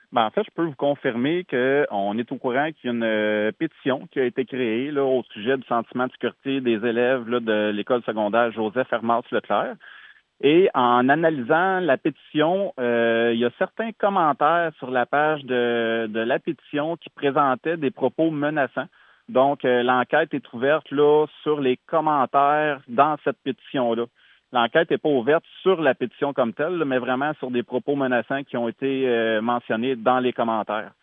En entrevue avec le service de nouvelles de M105